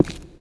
stone04.wav